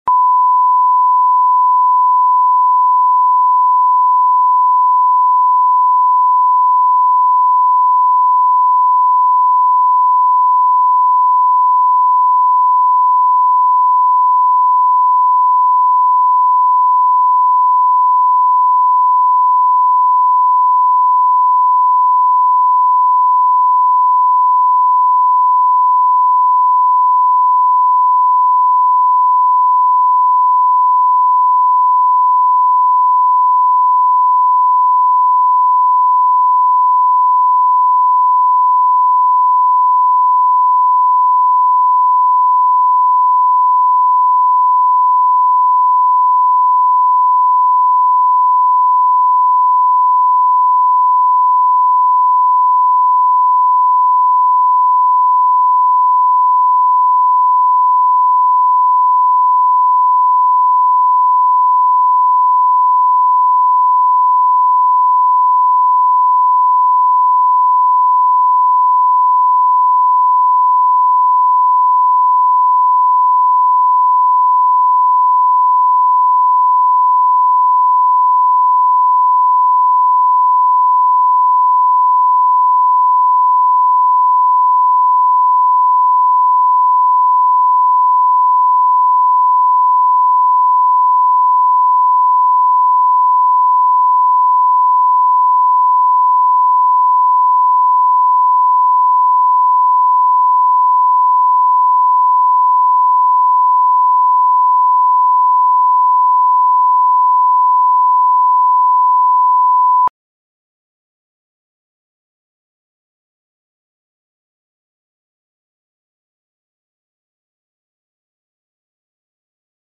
Аудиокнига Эффект бумеранга | Библиотека аудиокниг
Прослушать и бесплатно скачать фрагмент аудиокниги